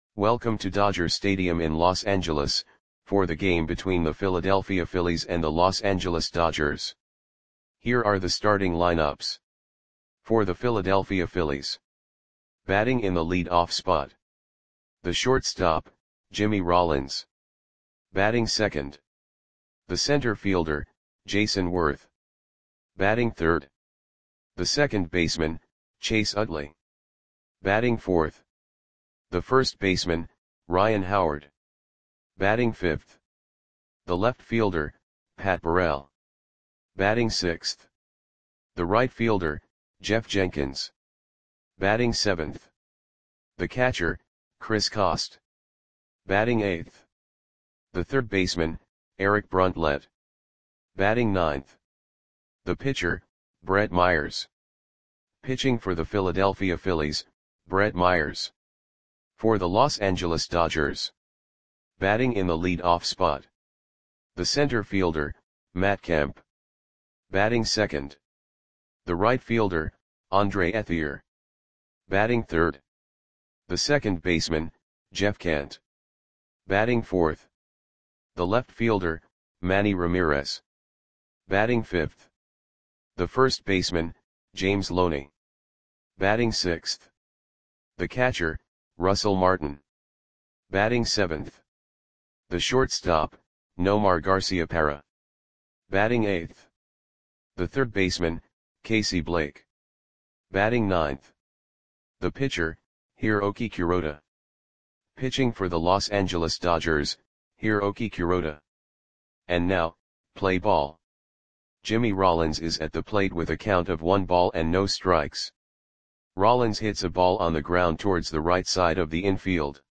Lineups for the Los Angeles Dodgers versus Philadelphia Phillies baseball game on August 14, 2008 at Dodger Stadium (Los Angeles, CA).
Click the button below to listen to the audio play-by-play.